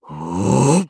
Kibera-Vox_Casting2_jp.wav